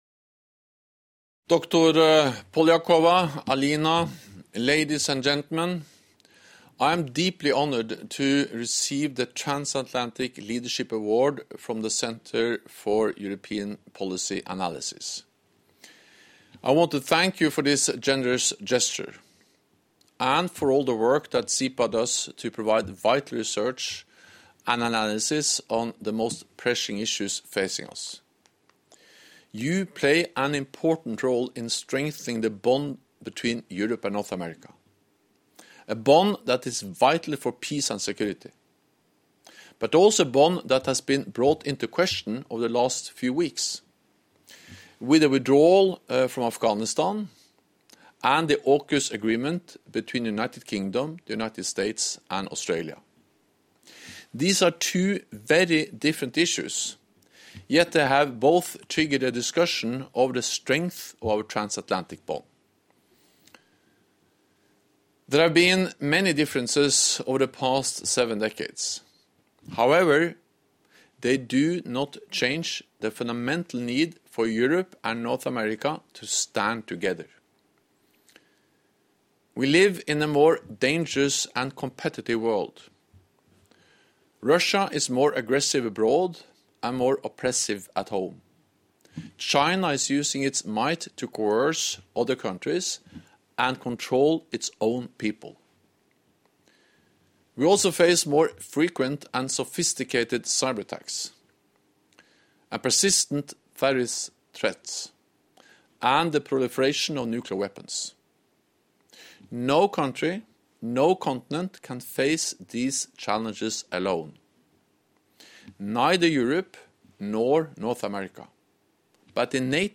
In a pre-recorded address, Mr. Stoltenberg thanked CEPA for its work to support transatlantic cooperation and resilience, stressing that the bond between Europe and North America remains ''vital for peace and security''.